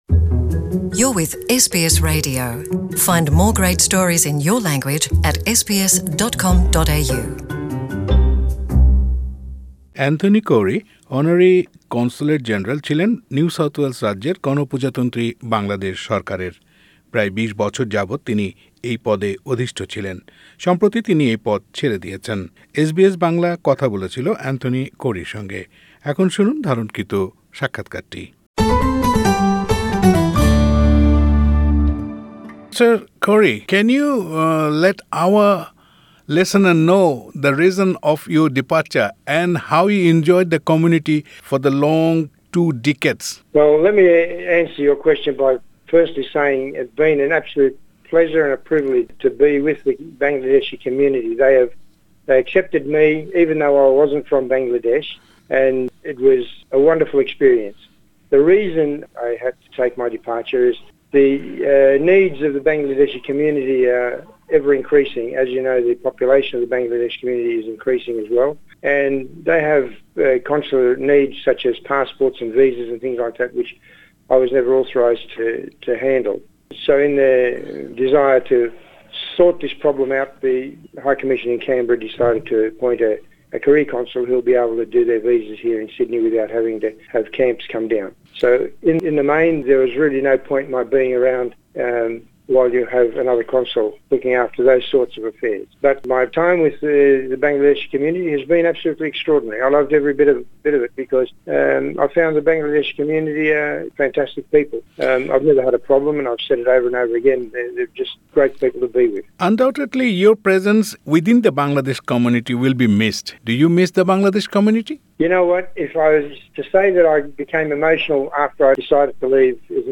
Interview with Anthony Khouri OAM